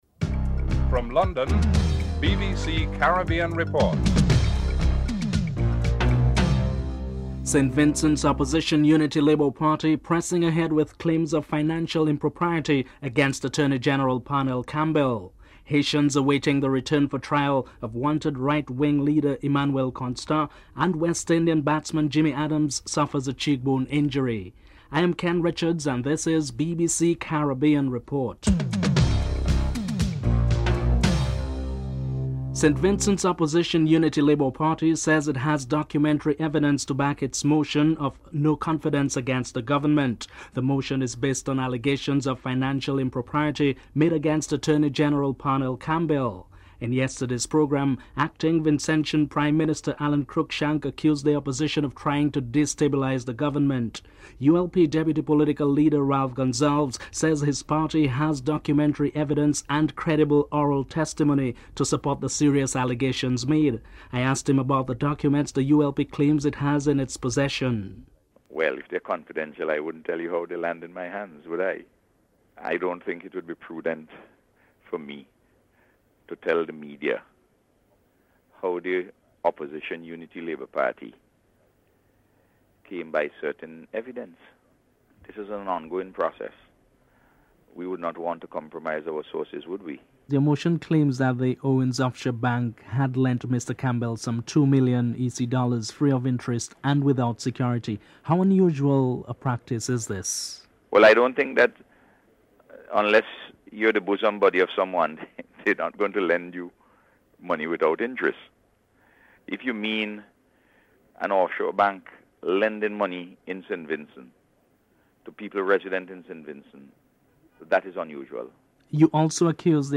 Caribbean Report interviews some Montserratians to find out how they are coping with the situation.